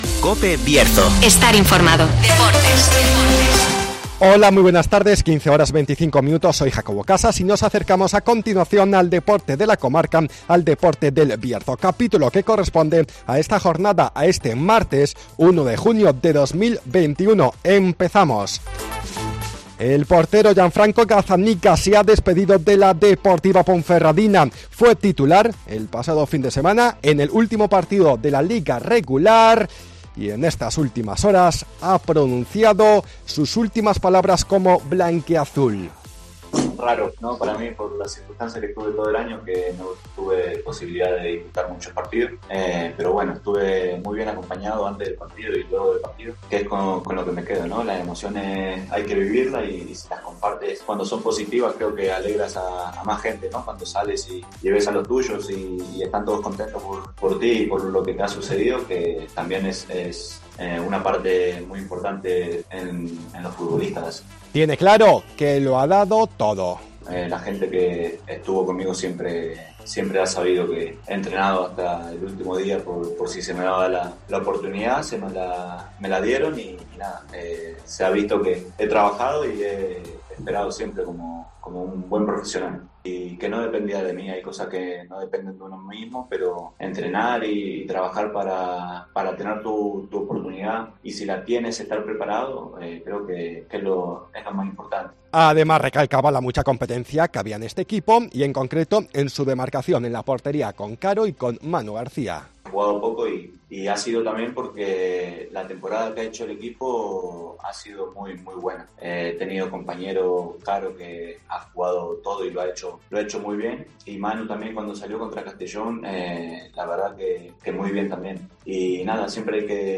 Escuchamos las palabras del guardameta Gianfranco Gazzaniga que se ha despedido de la Deportiva Ponferradina.